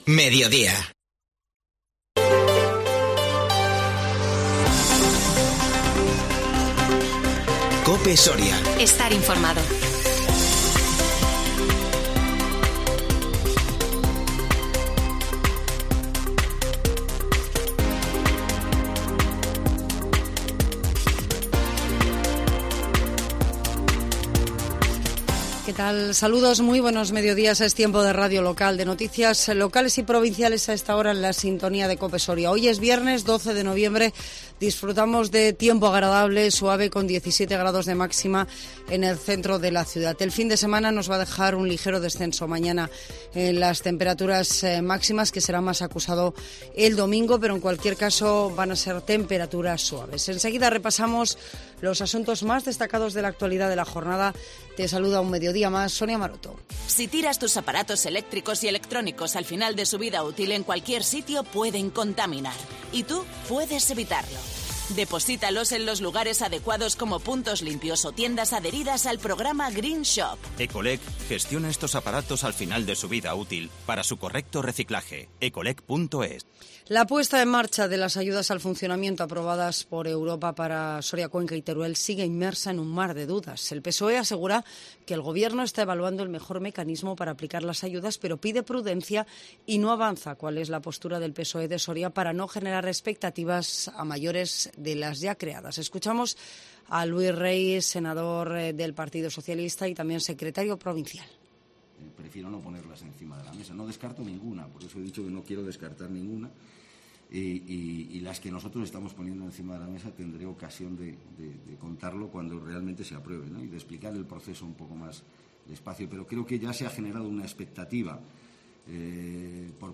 INFORMATIVO MEDIODÍA 12 NOVIEMBRE 2021